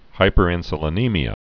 (hīpər-ĭnsə-lə-nēmē-ə)